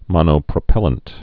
(mŏnō-prə-pĕlənt)